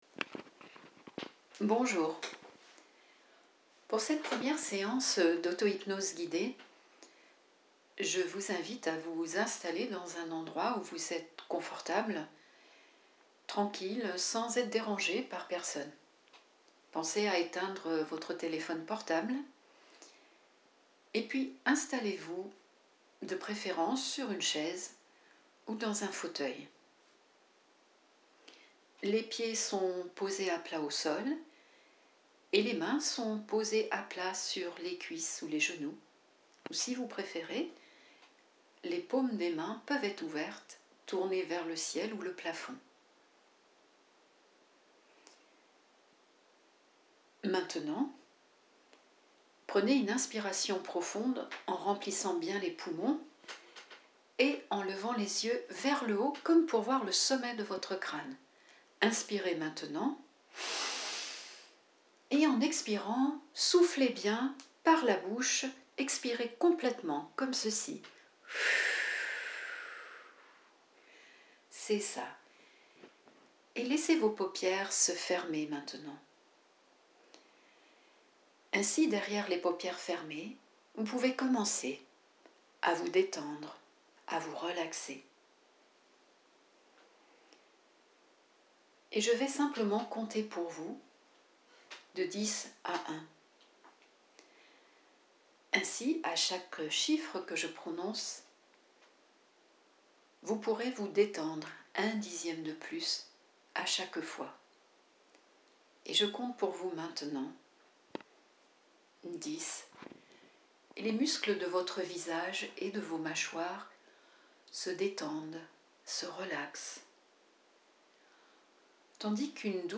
Séance autohypnose de 15 min offerte